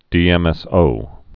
(dēĕm-ĕs-ō)